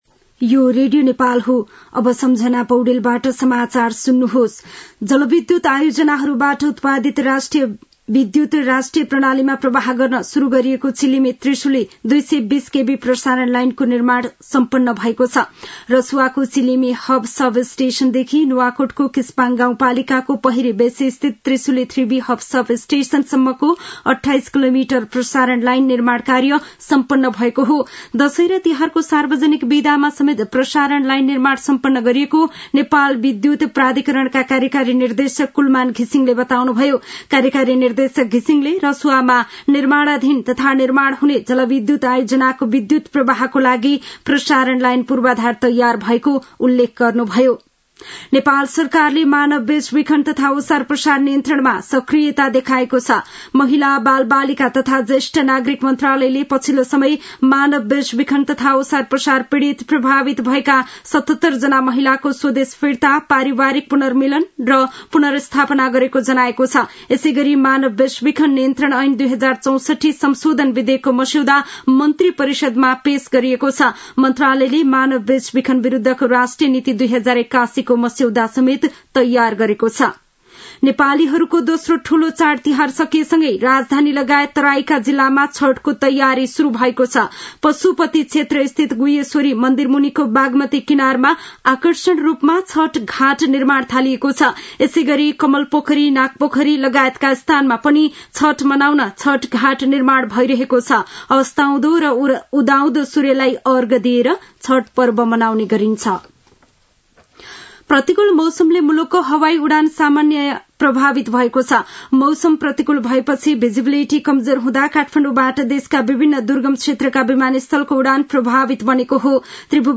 साँझ ५ बजेको नेपाली समाचार : २० कार्तिक , २०८१